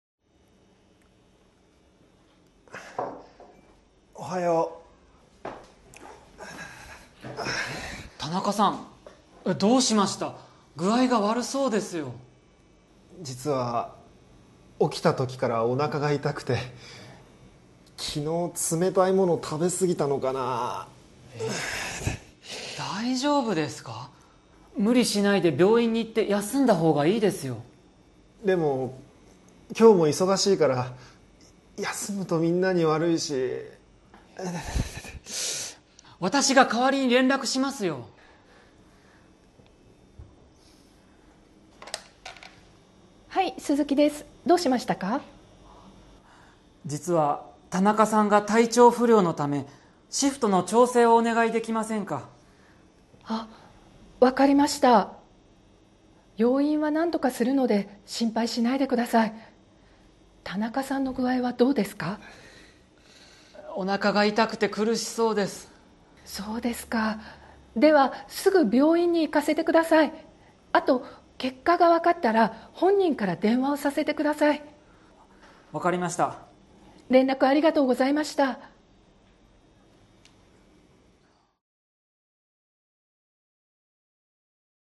Role-play Setup
Conversation Transcript